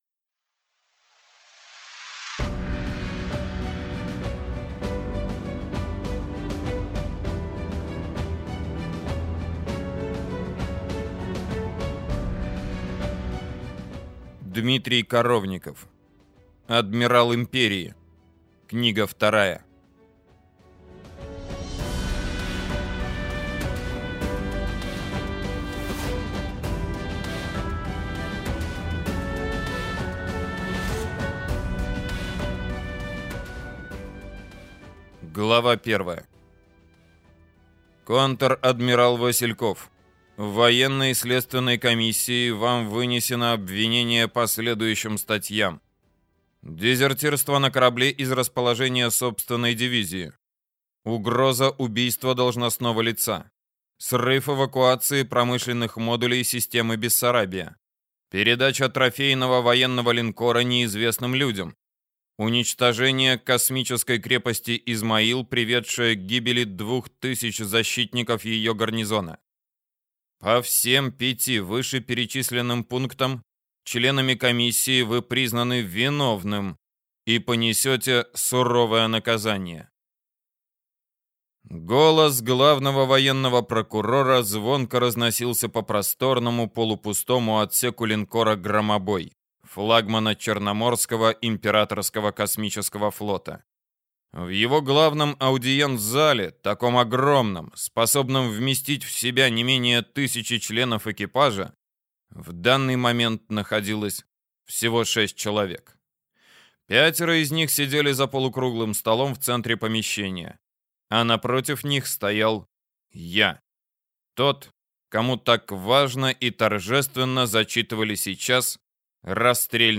Аудиокнига Адмирал Империи 2 | Библиотека аудиокниг